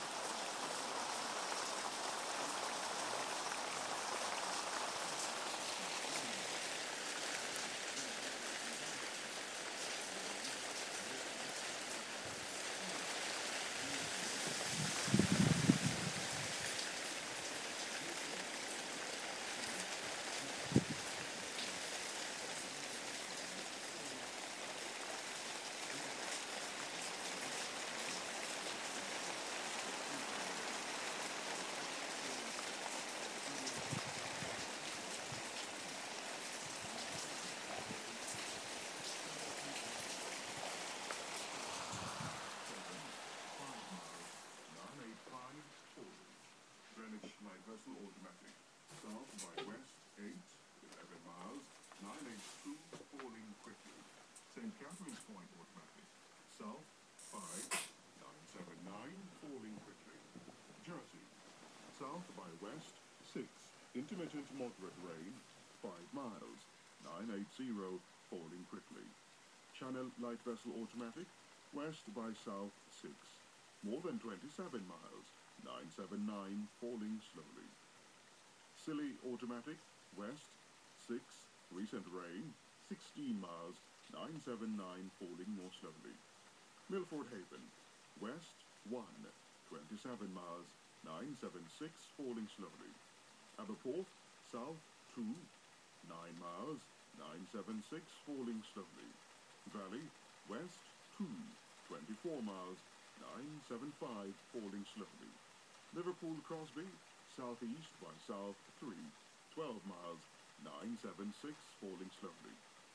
Torrential rain
reading the 00:45 shipping forecast